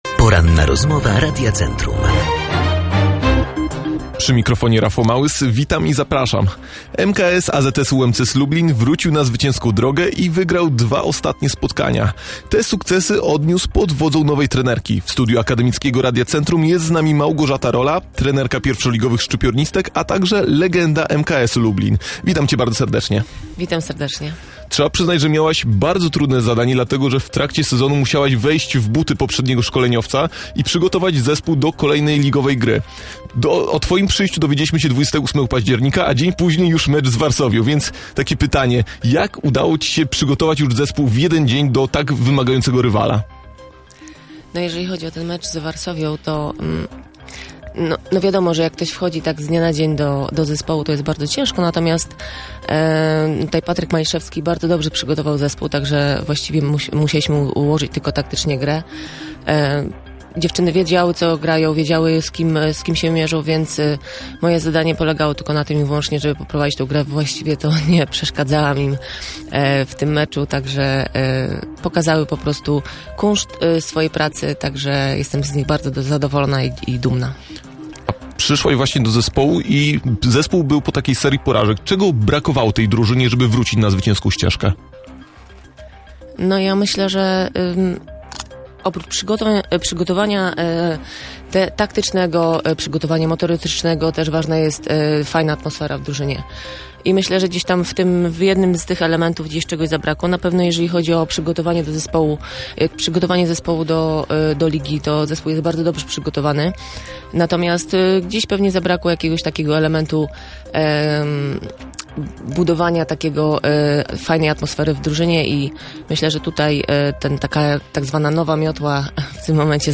Opublikowano w Aktualności, Audycje, Poranna Rozmowa Radia Centrum, Sport